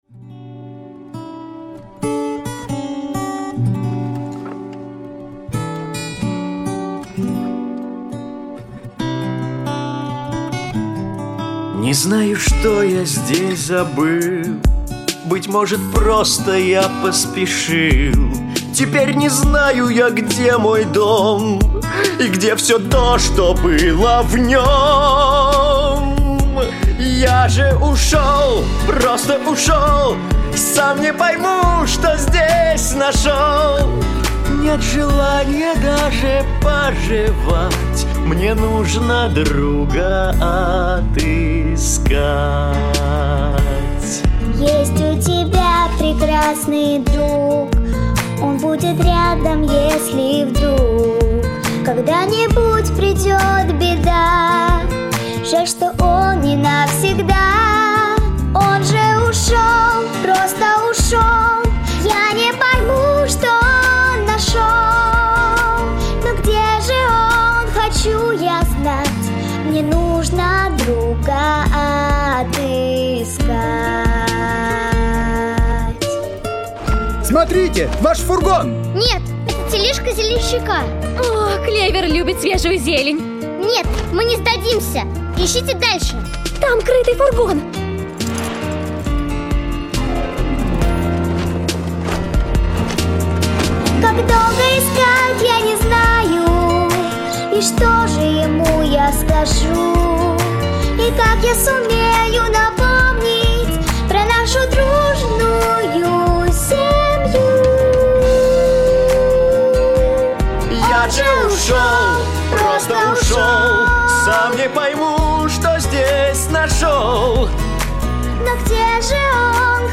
• Категория: Детские песни
🎶 Детские песни / Песни из мультфильмов